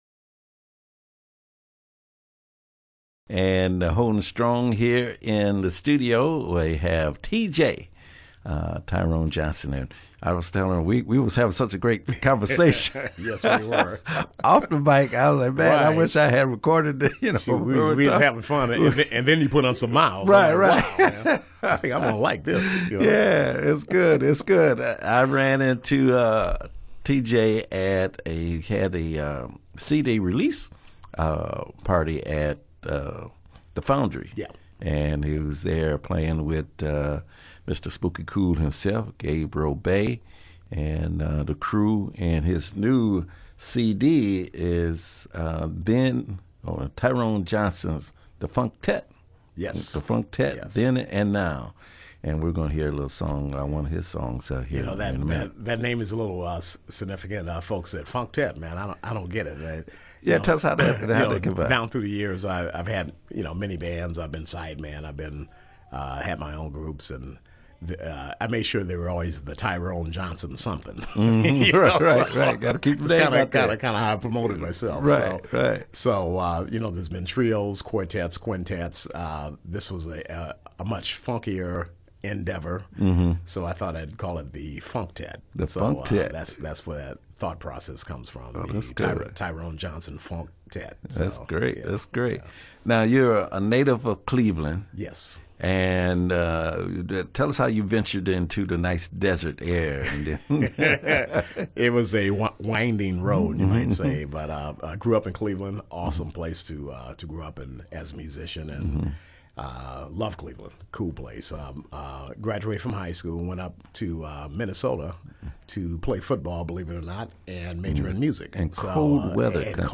Radio Phoenix Interview
Phoenix-Radio-Interview.mp3